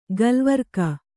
♪ galvarka